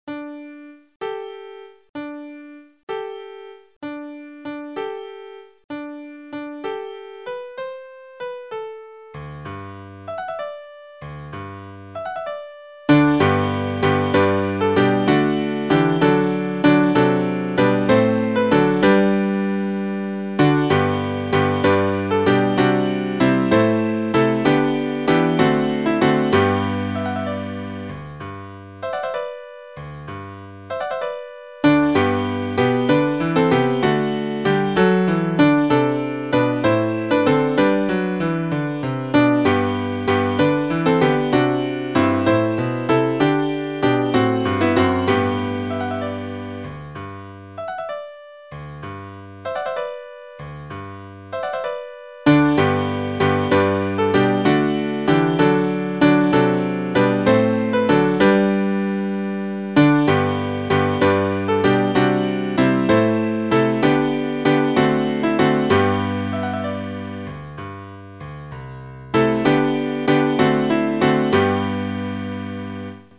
I am So Glad Each Christmas Eve (Knudsen)     Your part emphasized:     Soprano     Alto